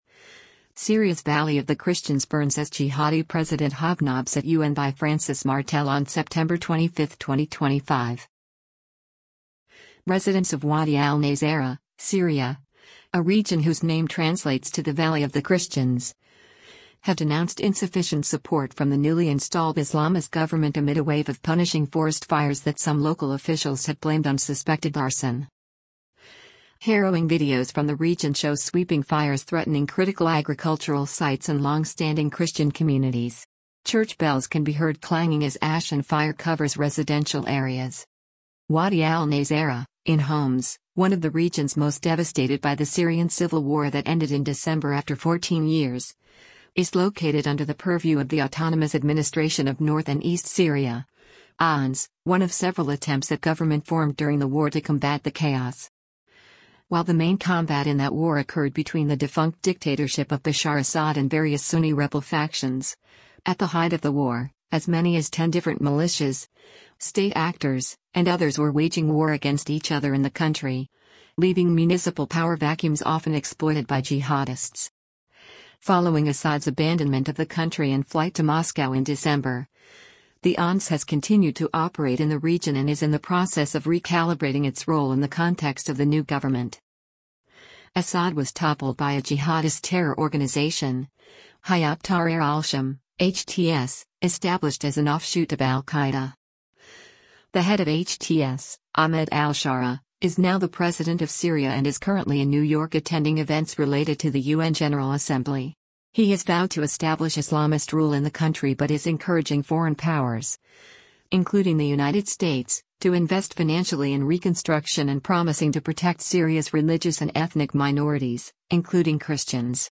Harrowing videos from the region show sweeping fires threatening critical agricultural sites and longstanding Christian communities. Church bells can be heard clanging as ash and fire covers residential areas.
أجراس الكنائس في بلدة حب نمرة بوادي النصارى تقرع للتحذير من حريق يقترب من المنازل، مع تفاقم خطورة الوضع..